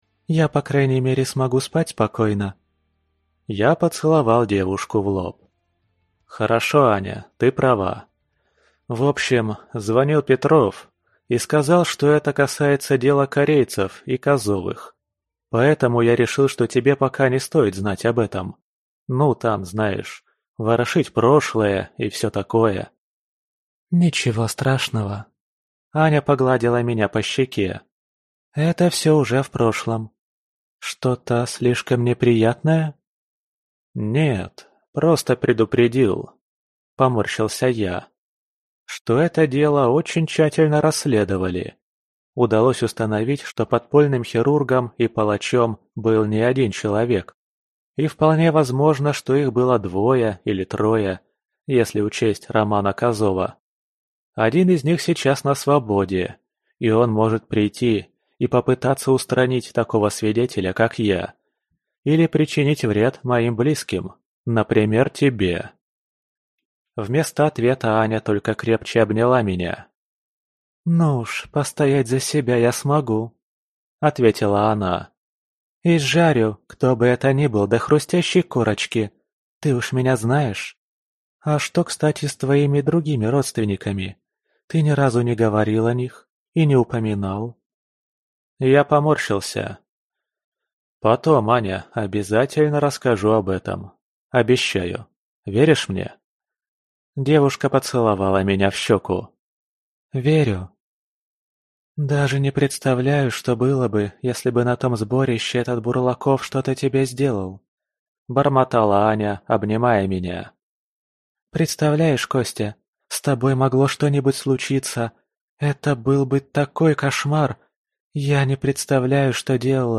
Аудиокнига Двойник. Книга 4. Глава Клана | Библиотека аудиокниг